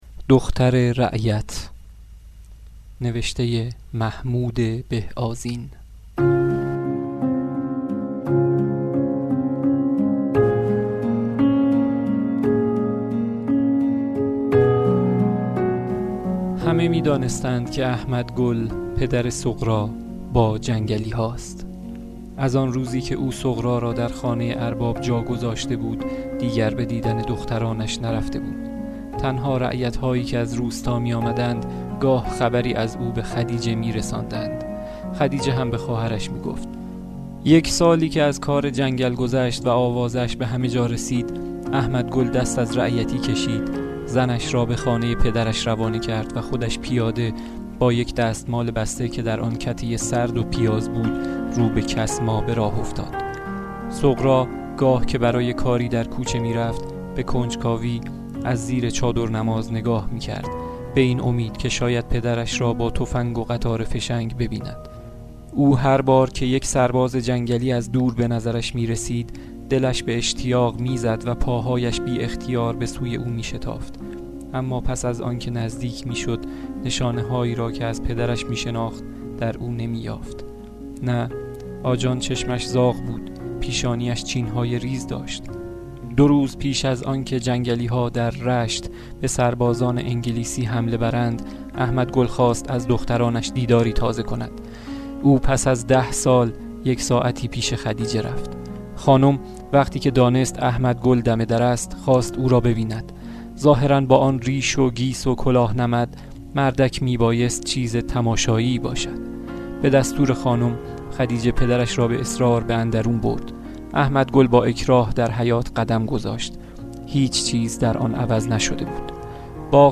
به بهانه سالگرد شهادت میرزا کوچک خان برشی از این رمان را خوانده‌م: